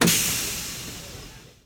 doors.wav